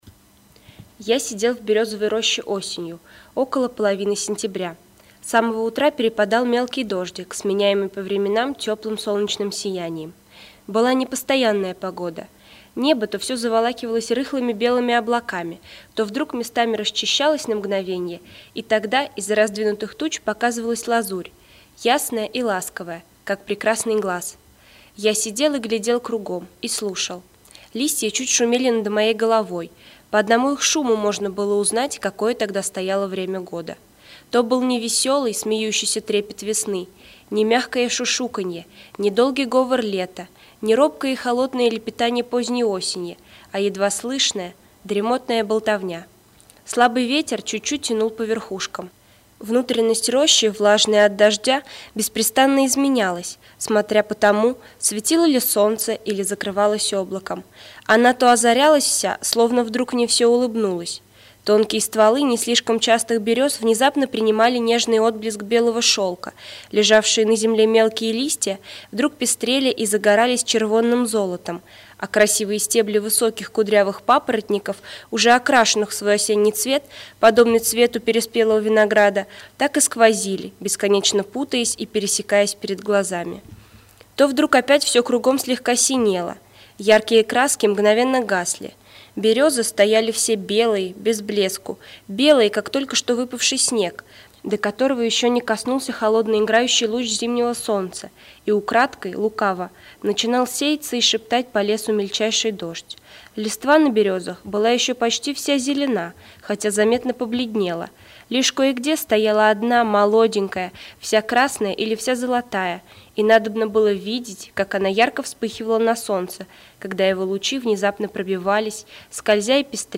Аудиокнига Свидание | Библиотека аудиокниг